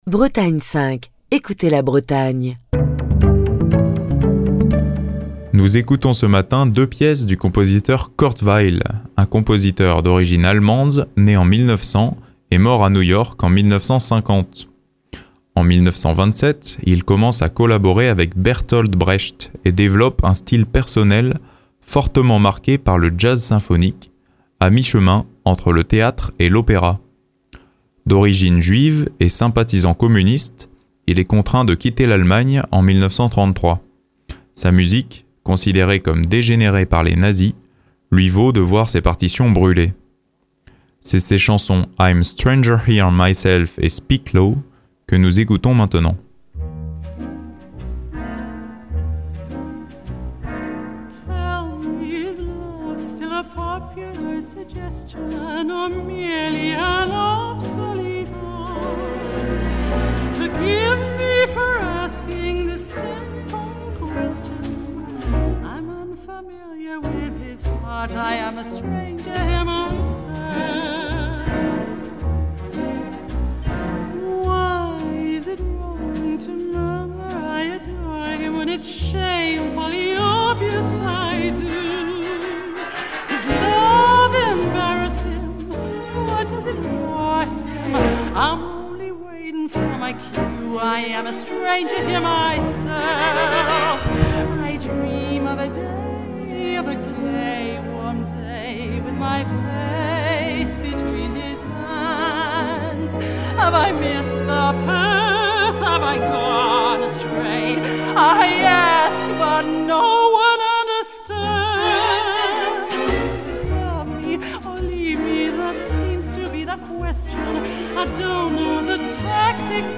mezzo-soprano